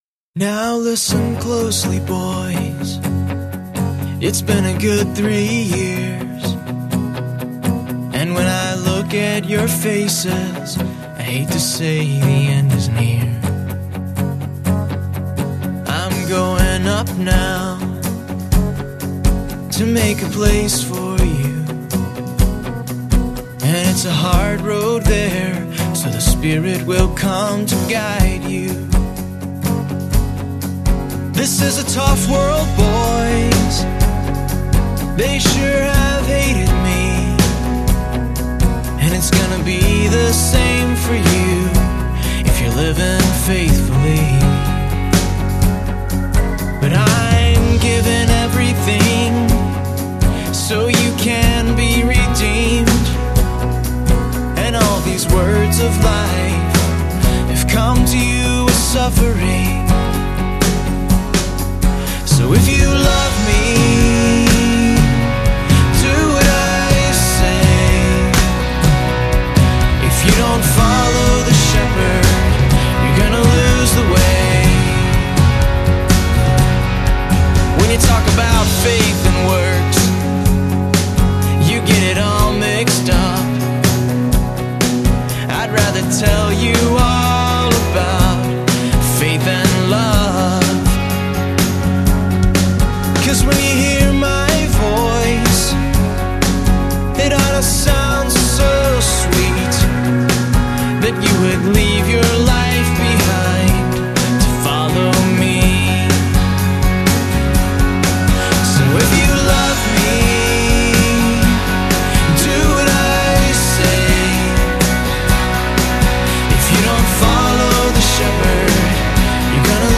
lead vocals, background vocals, acoustic guitars, harmonica
drums, percussion
bass guitar
electric guitars
piano, organ, rhodes, keys
accordian, mandolin, high strung guitar